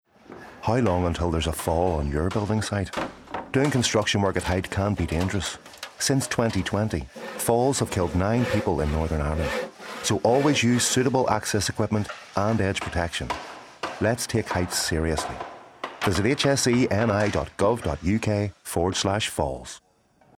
Take Heights Seriously - Radio advert | Health and Safety Executive for Northern Ireland, controlling risk together